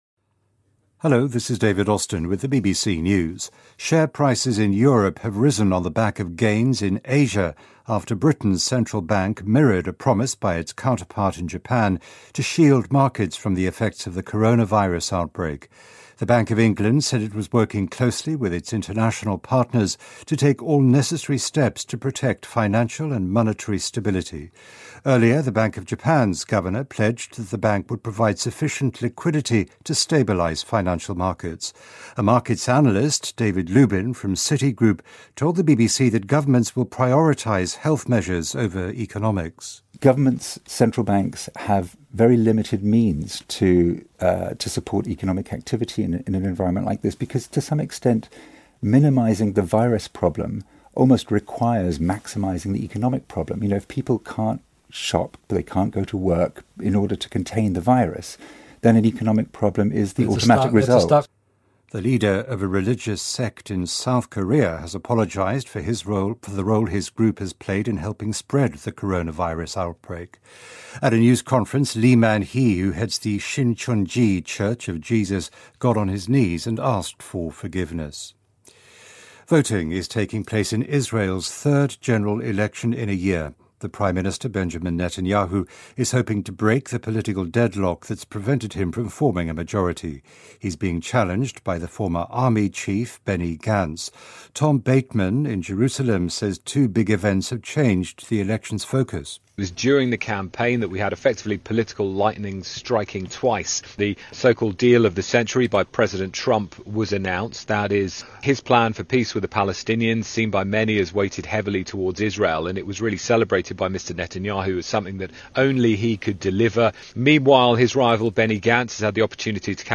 News
英音听力讲解:各国采取措施稳定金融市场